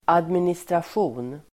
Uttal: [administrasj'o:n]